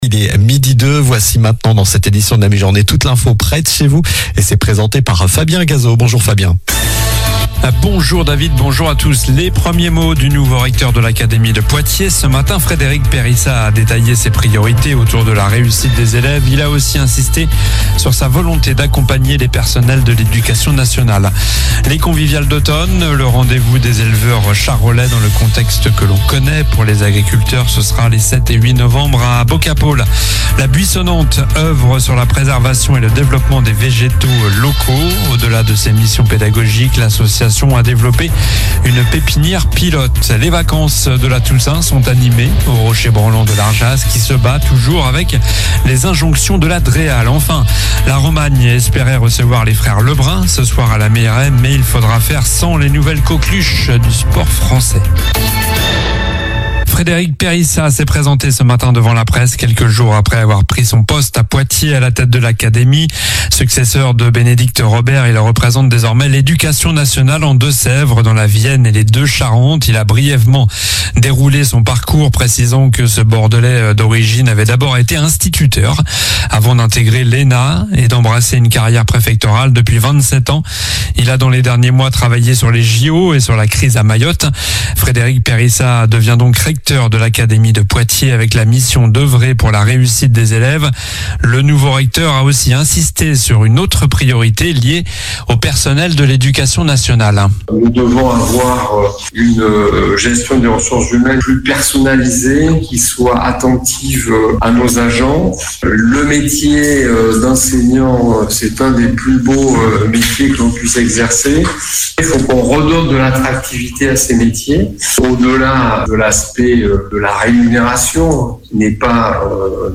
Journal du mardi 29 octobre (midi)